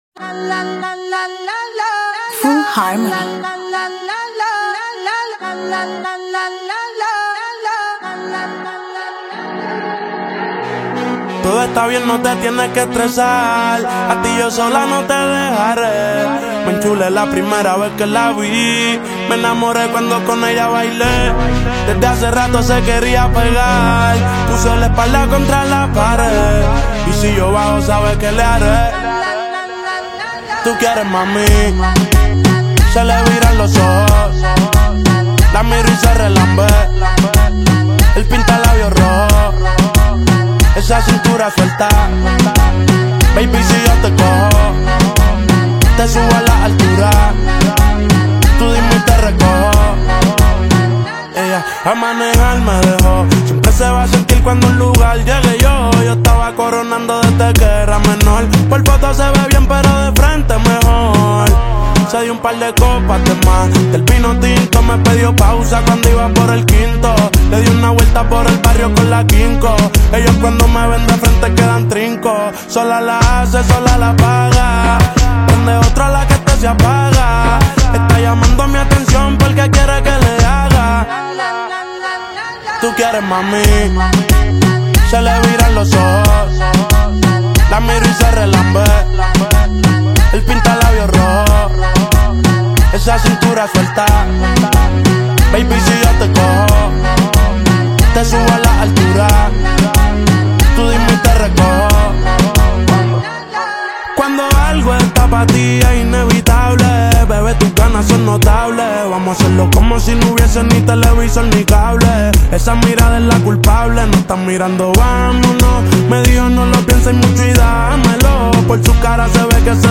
It is a catchy reggaetón track where he expresses